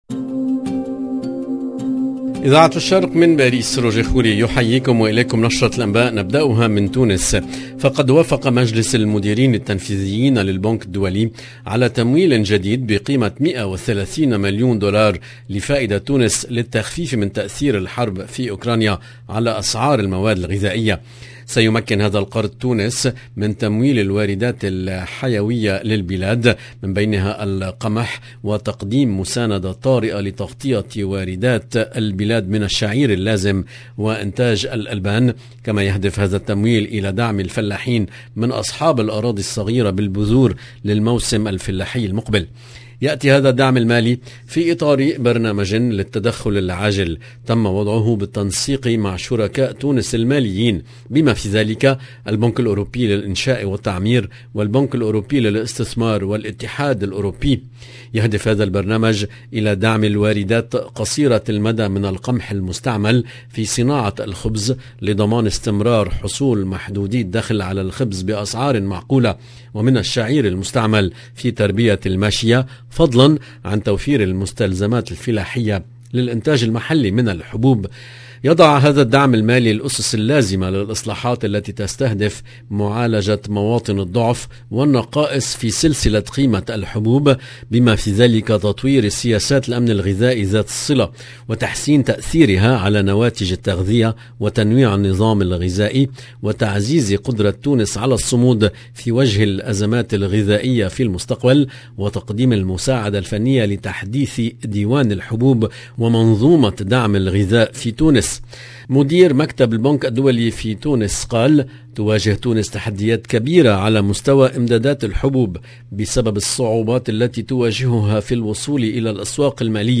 LE JOURNAL EN LANGUE ARABE DU SOIR DU